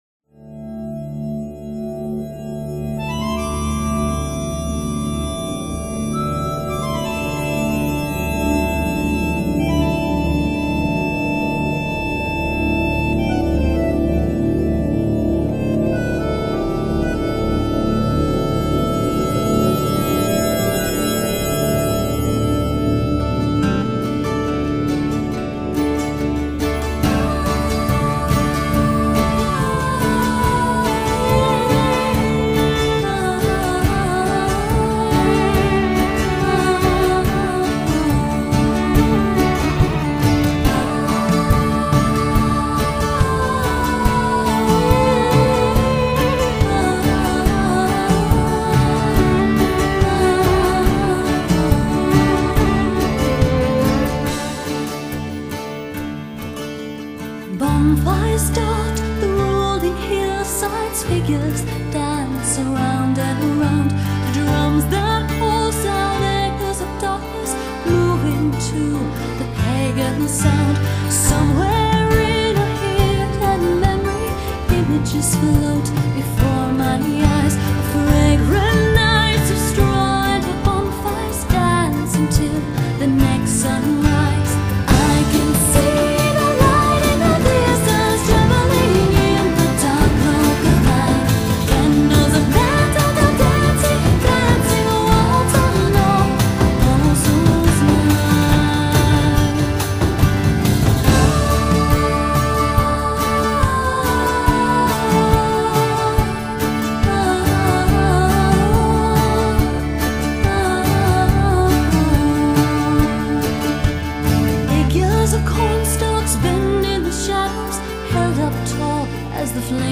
Genre: Celtic, New Age